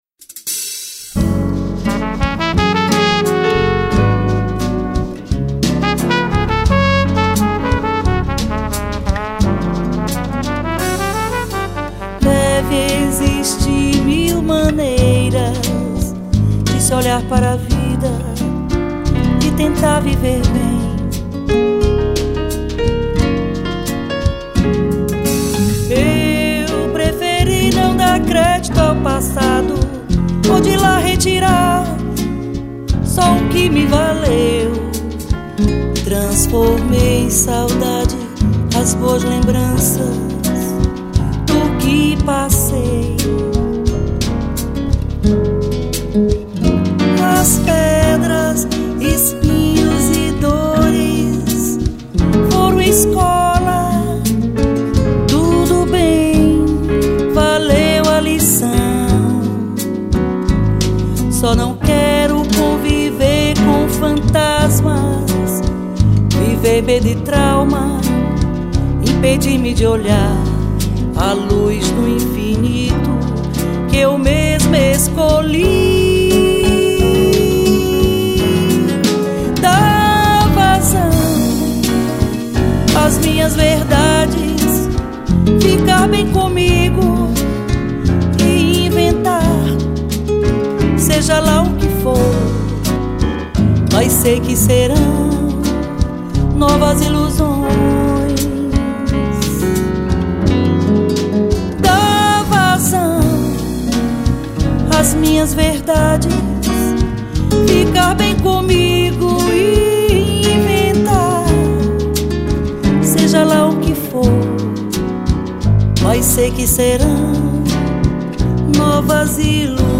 Cavaquinho, Pandeiro, Violao Acústico 6, Violao 7
Baixo Elétrico 6, Piano Acústico
Bateria, Percussão
Flauta, Sax Alto
Acoordeon